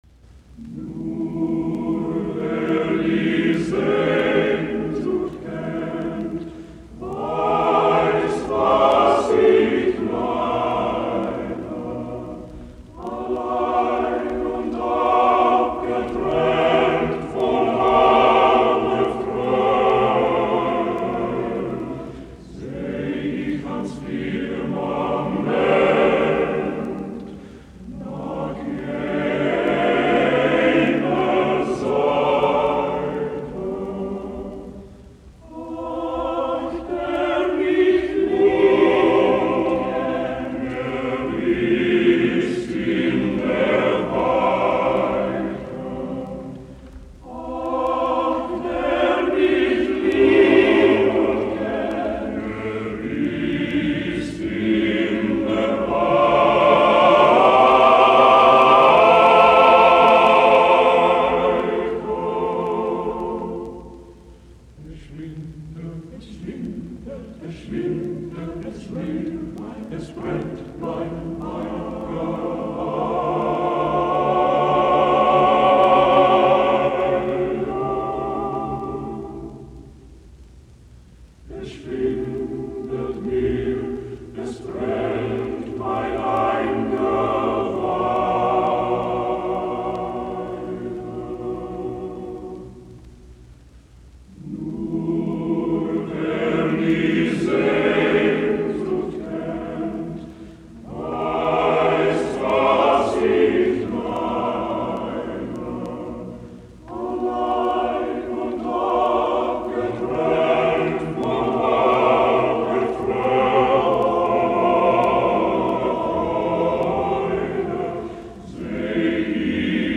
Sweet and low and Schubert songs for male chorus